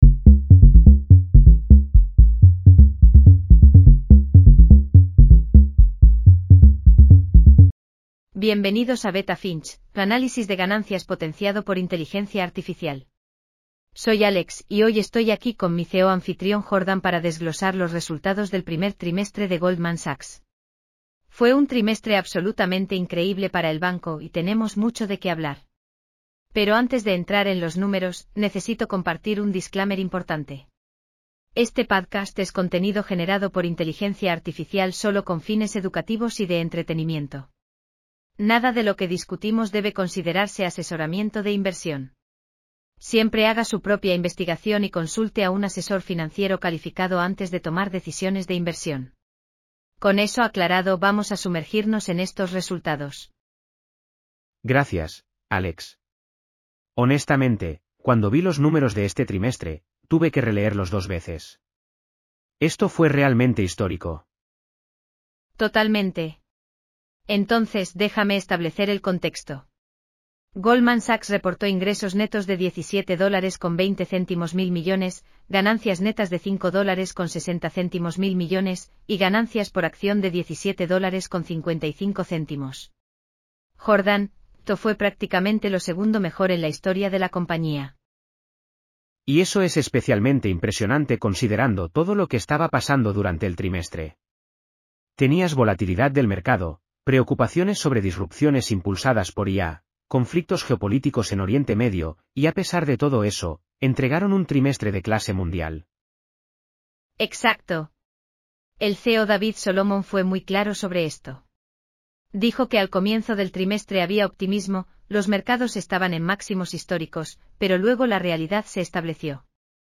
Bienvenidos a Beta Finch, tu análisis de ganancias potenciado por inteligencia artificial.